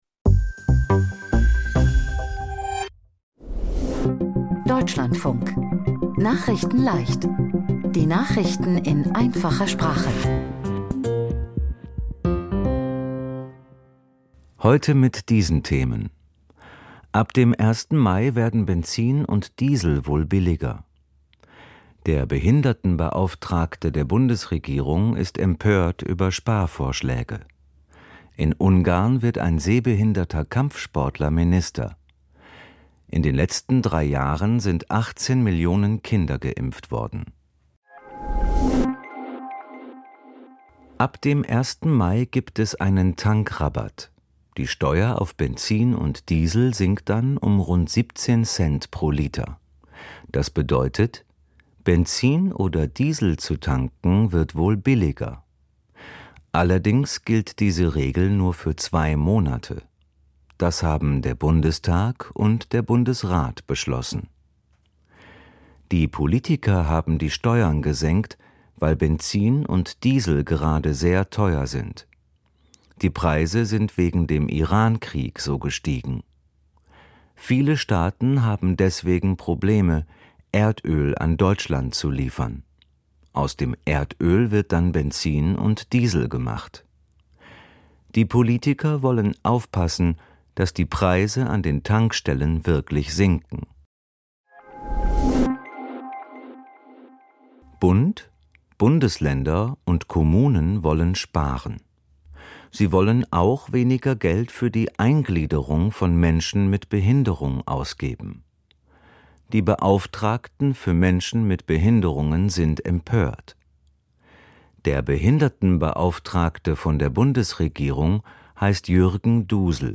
Nachrichten in Einfacher Sprache vom 24.04.2026